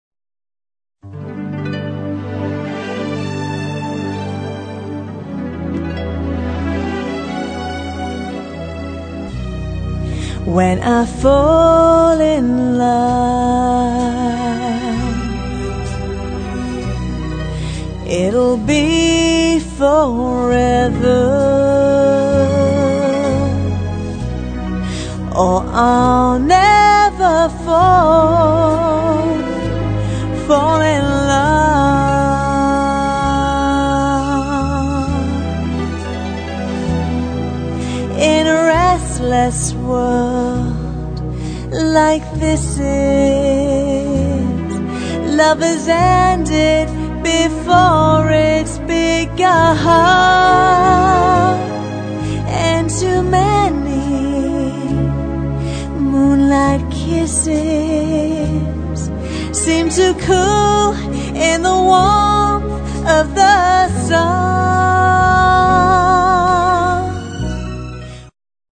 • Six-piece band
• Two female lead vocalists
Jazz